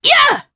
Demia-Vox_Attack3_jp_b.wav